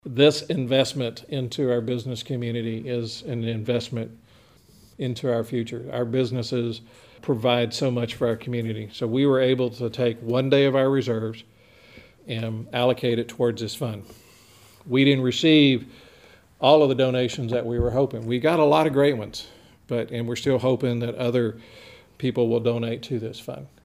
City Manager James Fisher gives an update on the city's response to COVID-19 at the Brenham City Council's meeting Thursday.